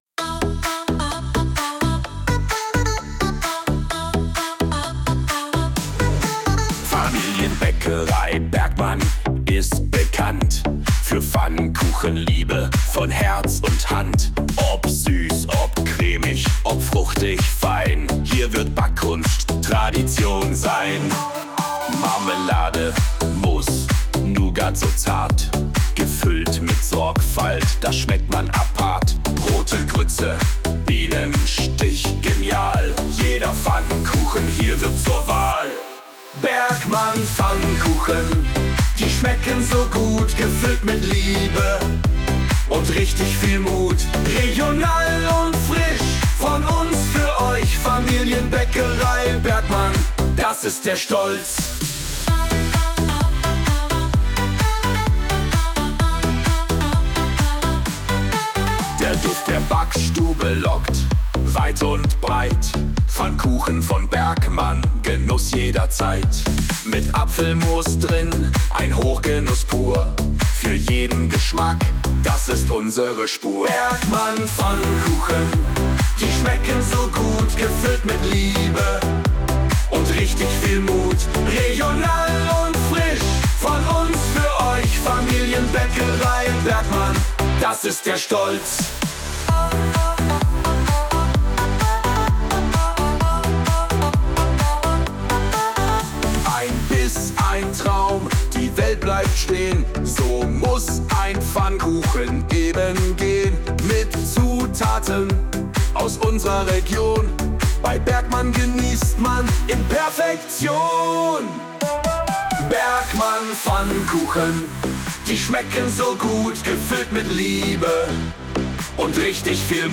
KI-generierten